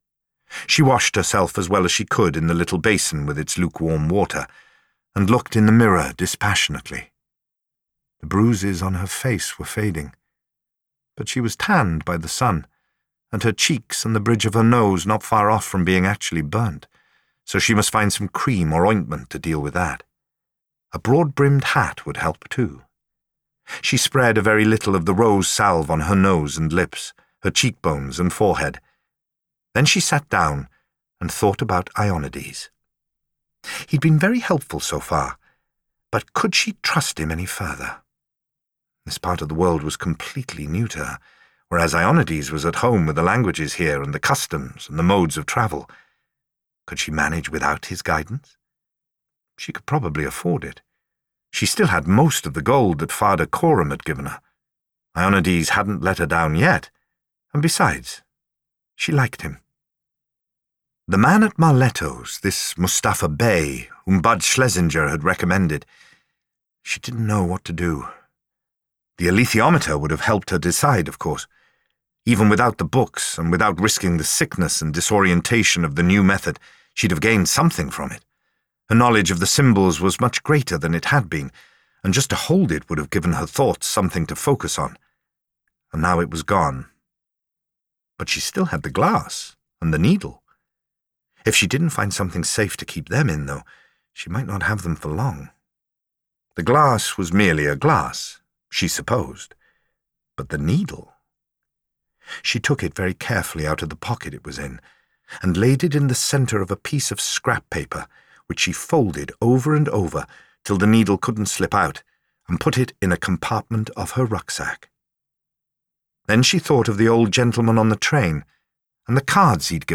Michael Sheen narra El Campo de Rosas